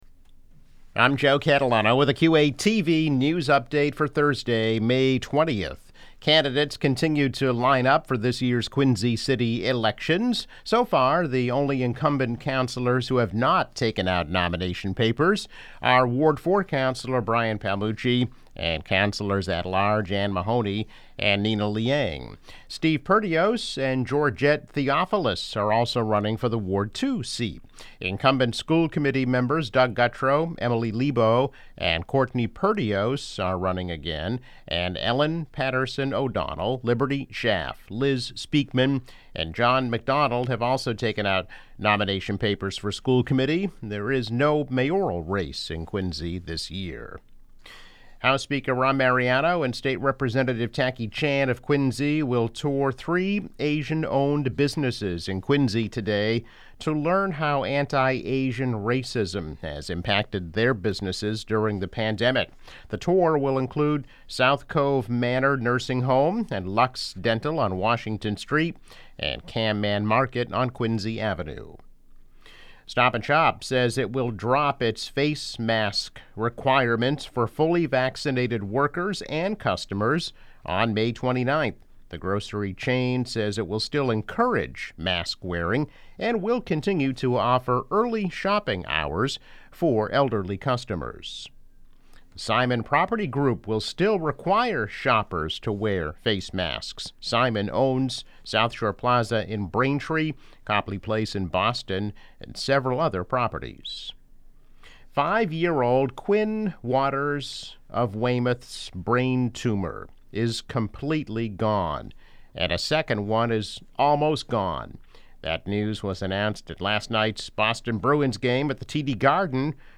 News Update - May 20, 2021